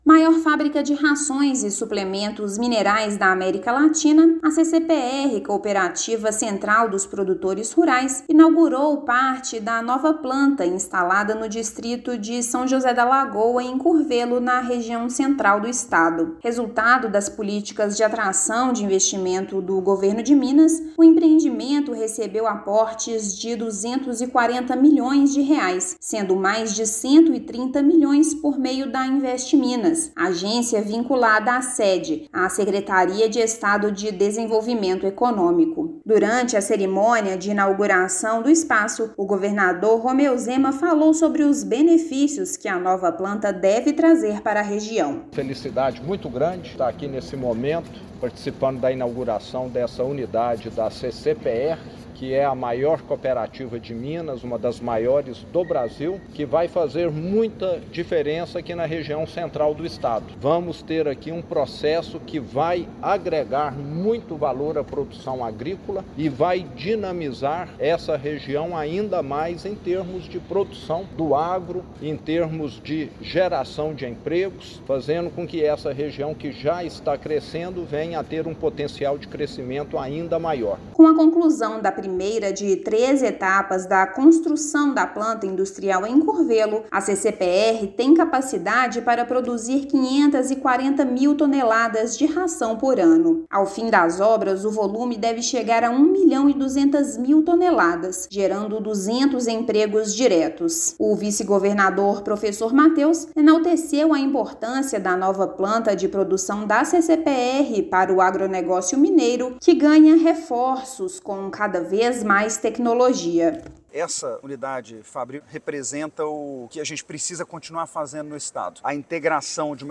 Empreendimento instalado em Curvelo, na região Central do estado, vai gerar 200 empregos diretos e contou com atração de investimentos de R$ 131 milhões do Governo de Minas. Ouça matéria de rádio.